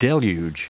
Prononciation du mot deluge en anglais (fichier audio)
Prononciation du mot : deluge